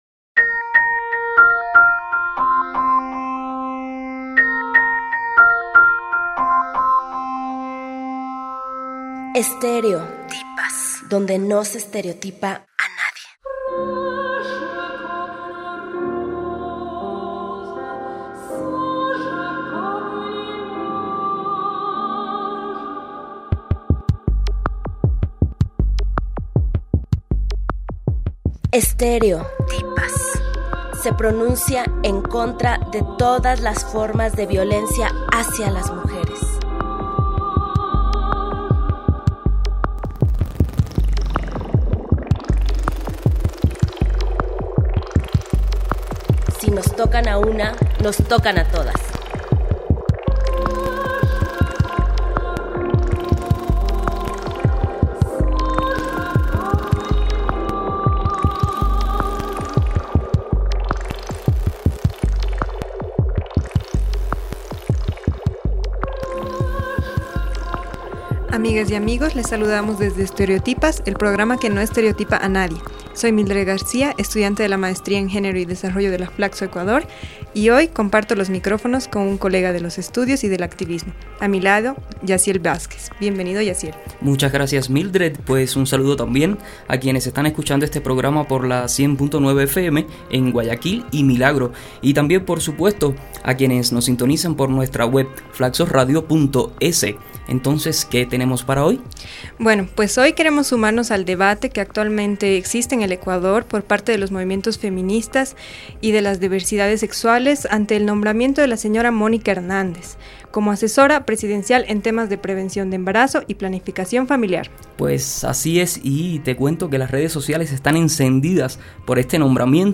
Nuestras invitadas nos comentan un poco acerca de su percepción en los cambios que se están dando en el ENIPLA y las perspectivas de los movimientos de mujeres acerca de estos cambios como también la polémica decisión de nombrar a la señora Mónica Hernández, como encargada de esta estrategia.